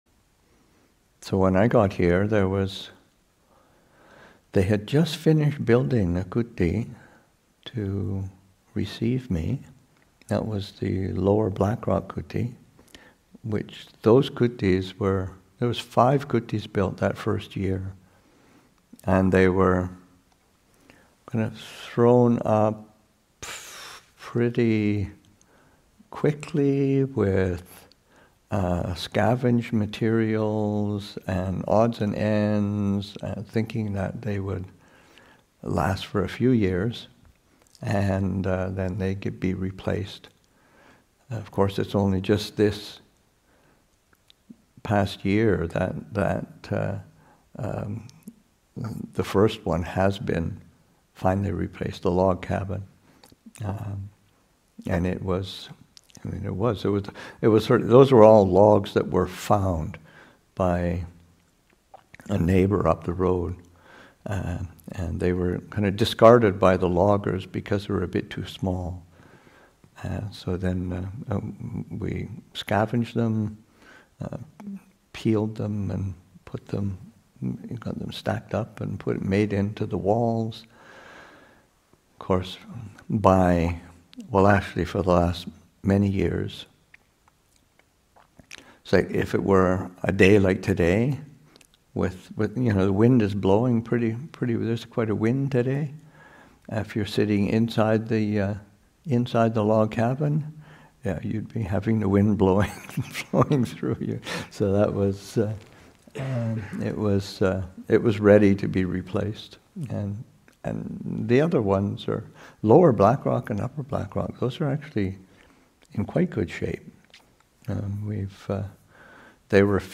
Story: Abhayagiri’s first kutis.